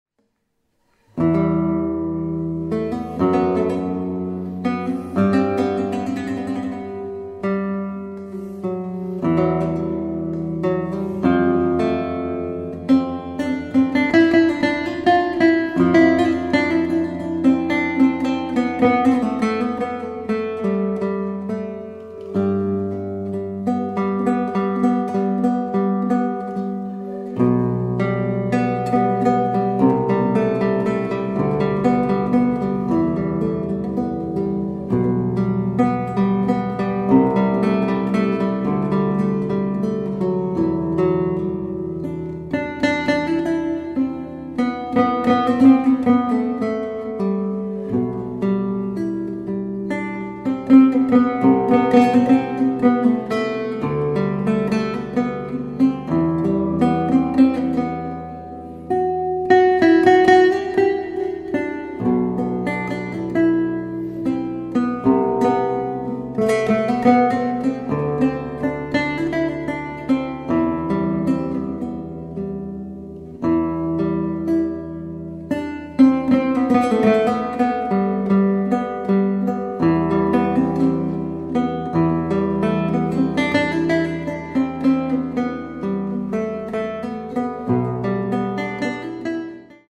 Here, my baroque lute sounds more radiant than ever before.
baroque lute